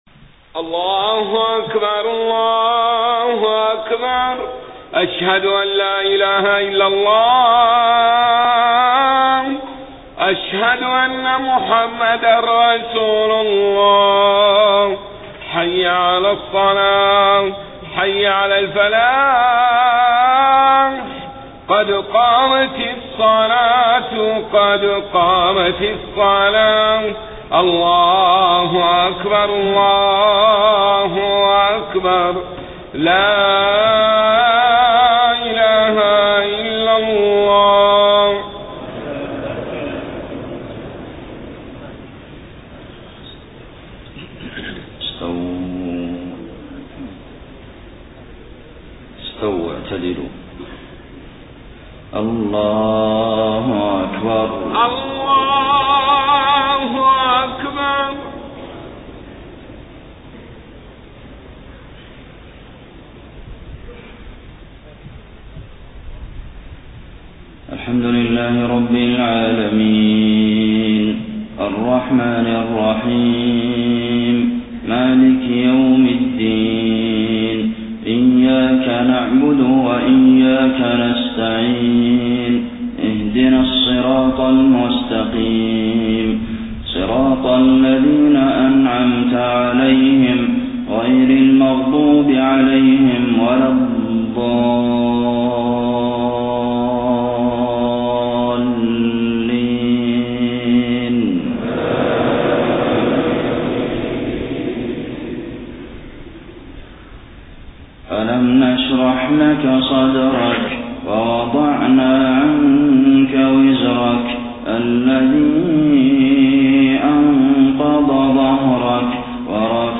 صلاة المغرب 30 صفر 1431هـ سورتي الشرح و التين > 1431 🕌 > الفروض - تلاوات الحرمين